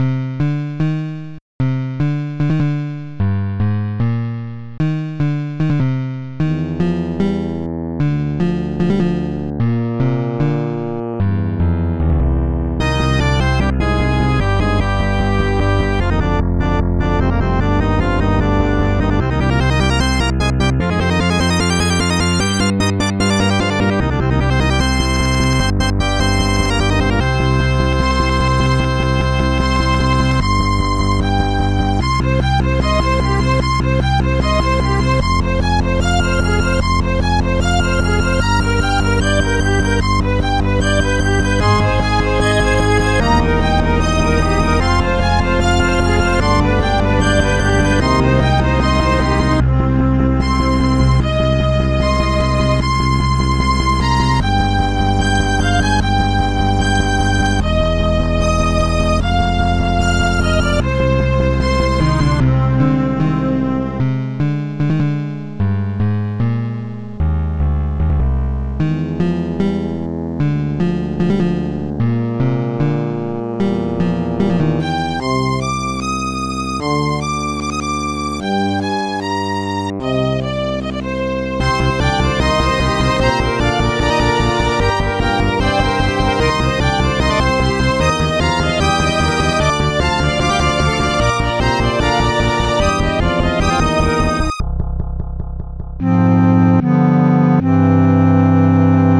Tracker DSIK DSM-format Tracks 8 Samples 31 Patterns 16